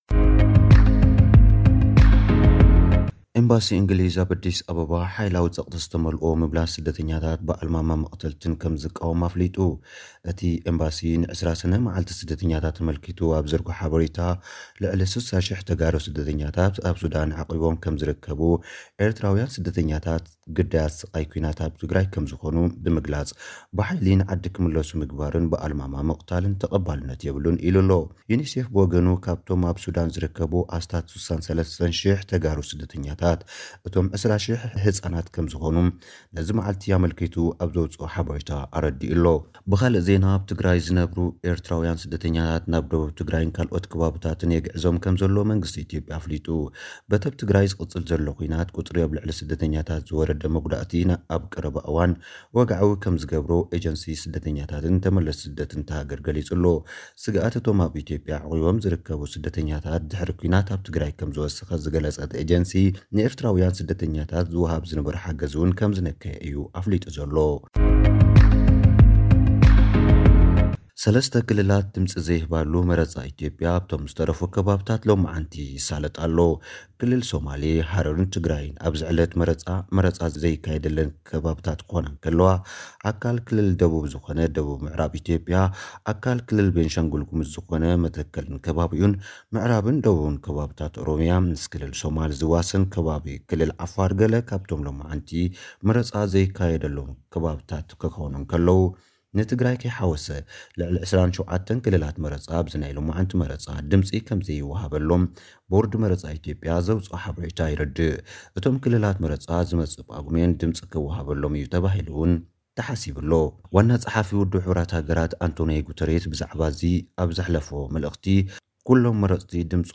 ሓጸርቲ ጸብጻባት፥